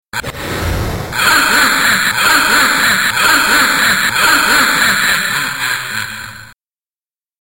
cackles sadistically after the credits.
splatter_masklastlaugh.mp3